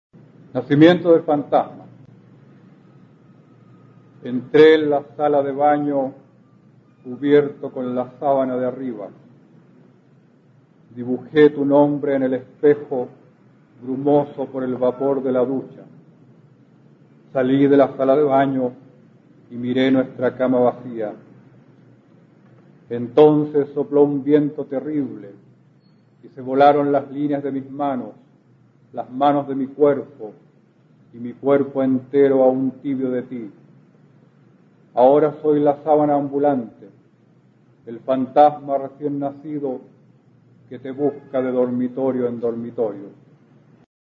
Escucharás al poeta chileno Óscar Hahn, perteneciente a la Generación del 60, recitando su poema Nacimiento del fantasma, del libro "Mal de amor" (1981).